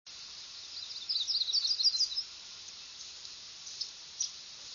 Yellow Rumped Warbler
warbler_YR_and_chits_860-3.wav